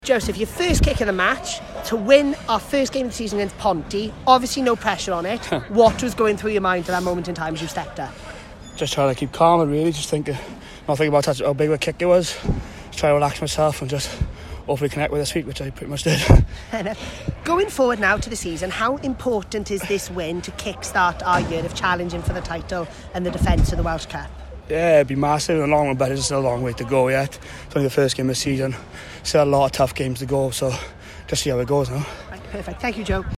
Post match Interviews.